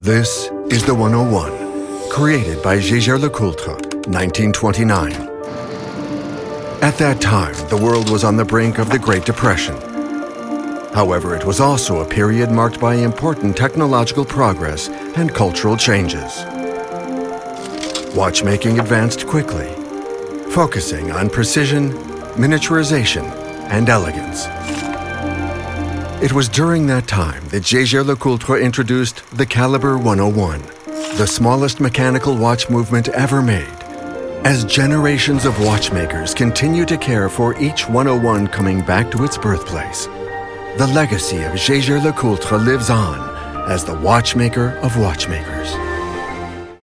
Natural, Versatile, Warm, Mature, Corporate
Explainer